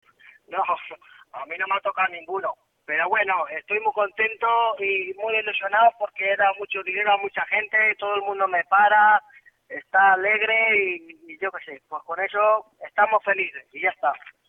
Todo el mundo me para por la calle... ¡estamos felices!”, apostilla con entusiasmo.